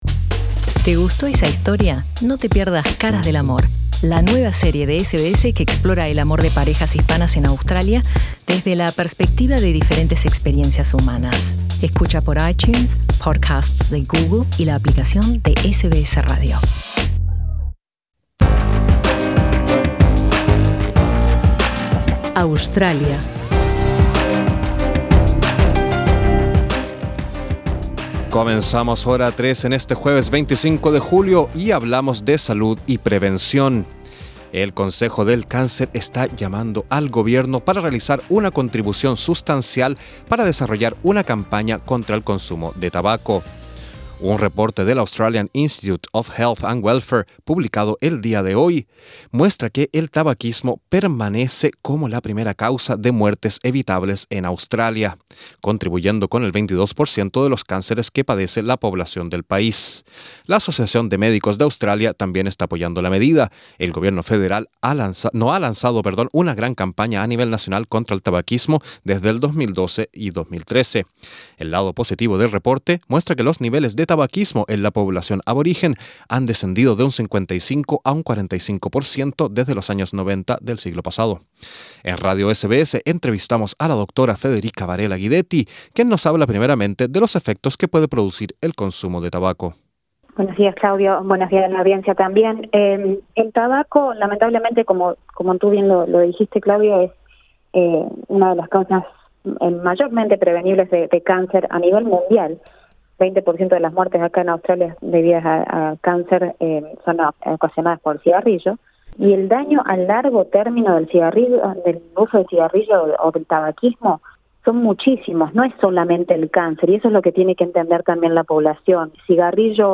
En Radio SBS entrevistamos